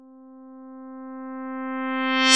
描述：为moombahton或任何你想要的东西提供了很好的快速上升器 如果你使用它，让我看看你的作品。 顺便说一句，完全免收版权费用，所以请尽情使用它吧
Tag: 108 bpm Moombahton Loops Fx Loops 407.95 KB wav Key : Unknown